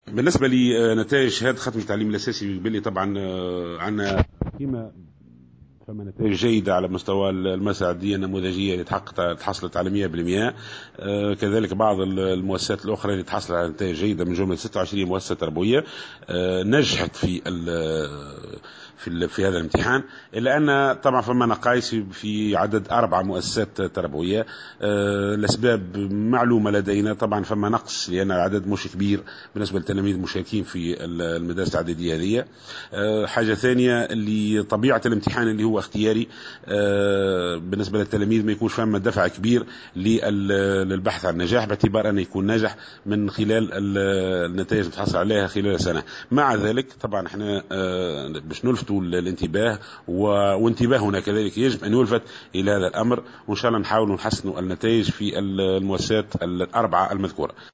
وأضاف في تصريح للجوهرة اف ام اليون، أنه تم تسجيل نسبة 0 بالمائة في 4 مؤسسات، تعود أساسا إلى نقص عدد التلاميذ المترشحين لمناظرة "النوفيام".